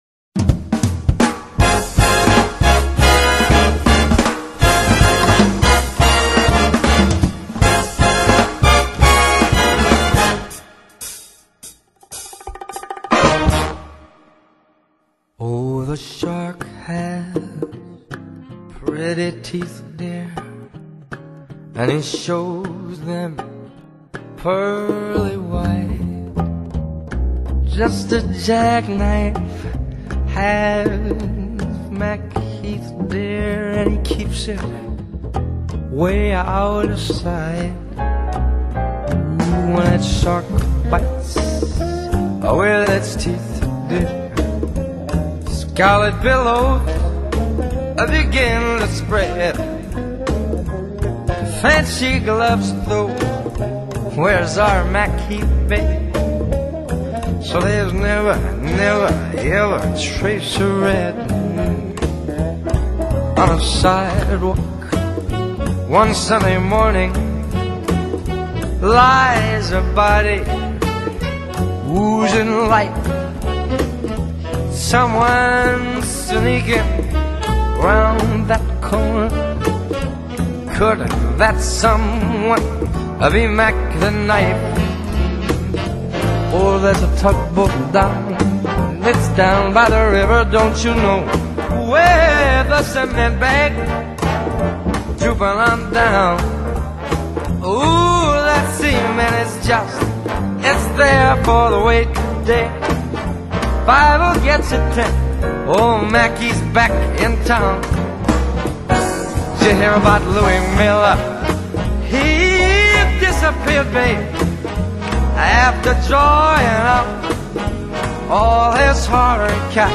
音乐类型：爵士乐
管号乐铺排与华丽编曲